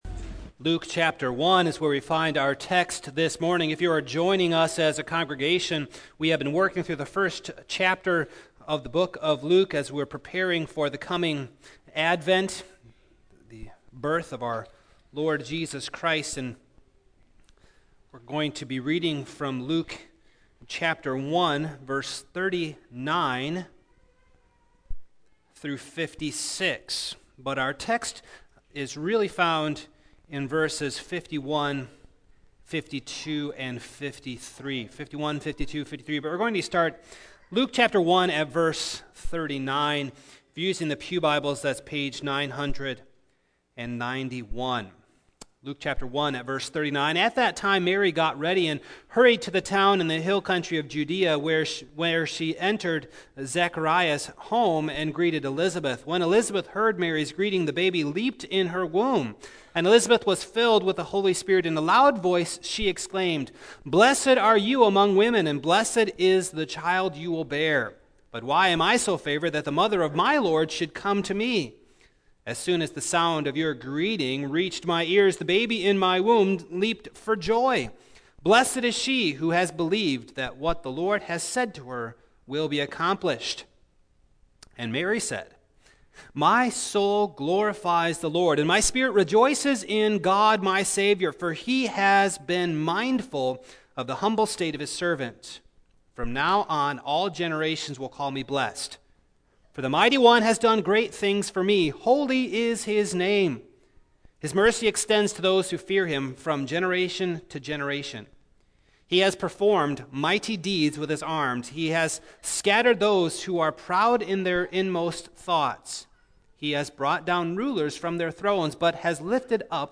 2016 The Contrast of Mary’s Song Preacher